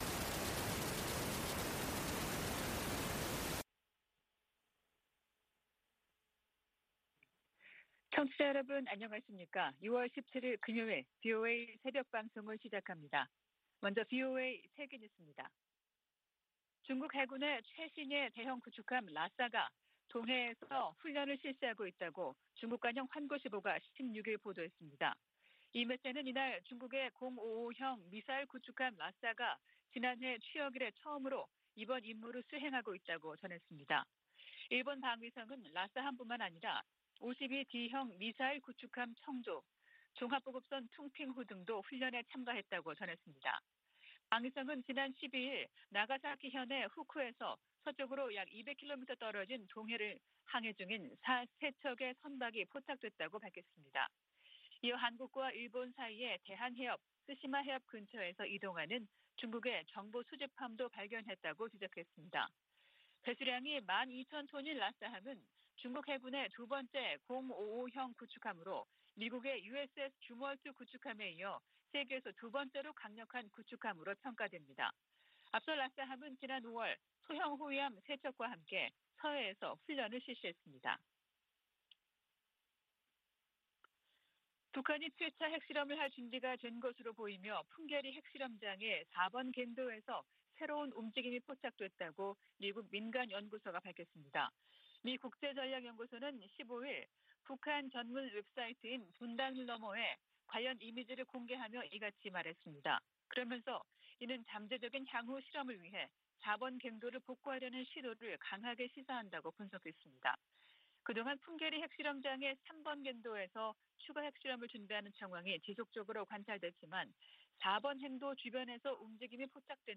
VOA 한국어 '출발 뉴스 쇼', 2022년 6월 17일 방송입니다. 미 하원 세출위원회 국방 소위원회가 북한 관련 지출을 금지하는 내용을 담은 2023 회계연도 예산안을 승인했습니다. 북한 풍계리 핵실험장 4번 갱도에서 새로운 움직임이 포착됐다고 미국의 민간연구소가 밝혔습니다. 북한이 지난해 핵무기 개발에 6억4천200만 달러를 썼다는 추산이 나왔습니다.